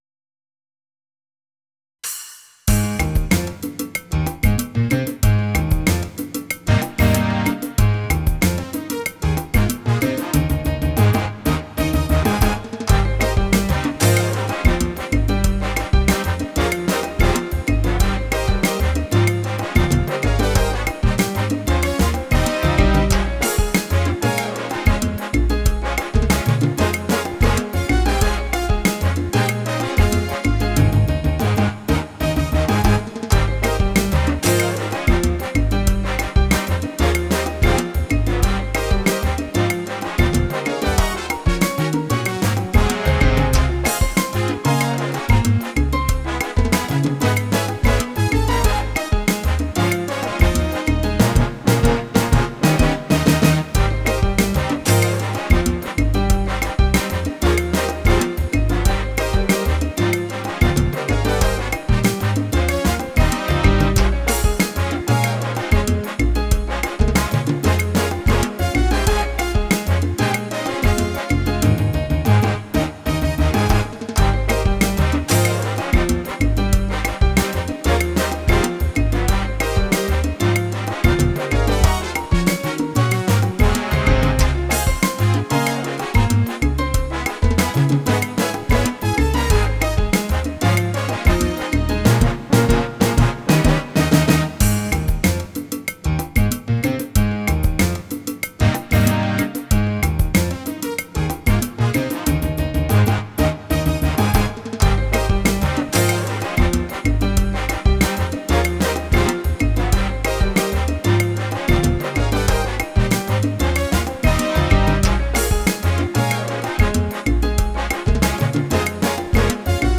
SALSAS.wav